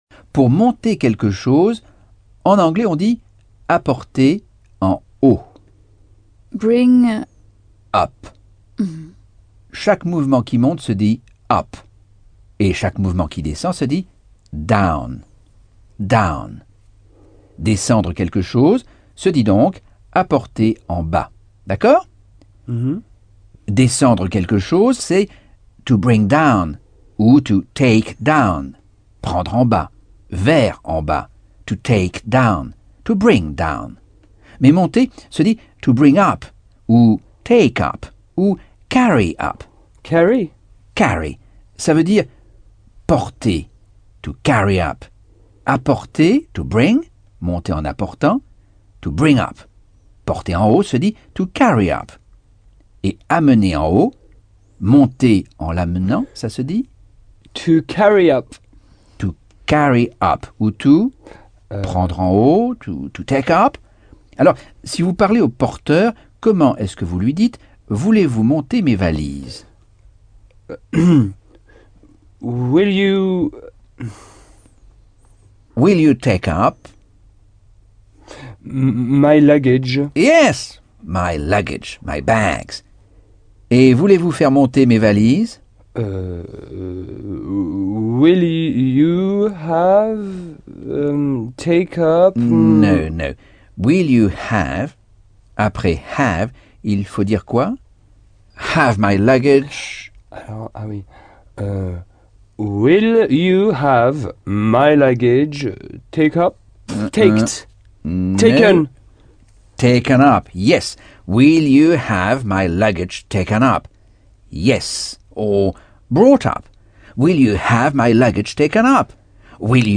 Leçon 5 - Cours audio Anglais par Michel Thomas - Chapitre 10